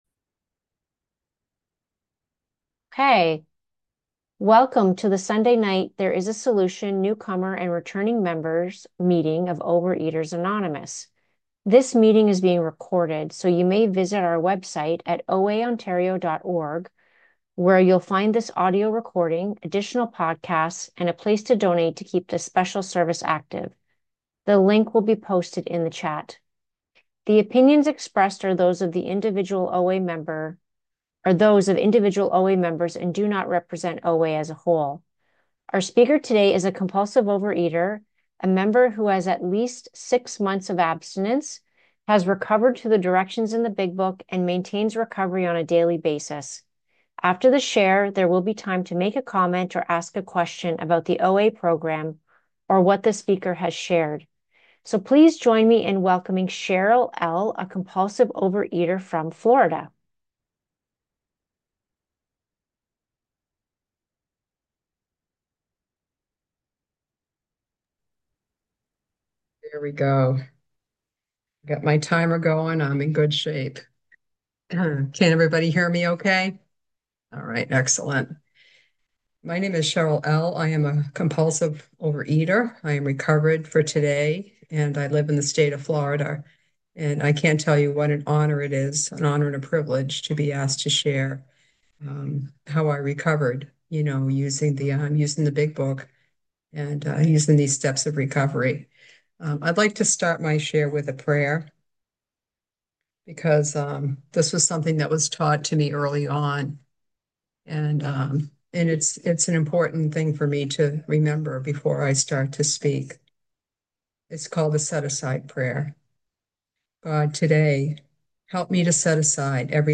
Overeaters Anonymous Central Ontario Intergroup Speaker Files OA Newcomer Meeting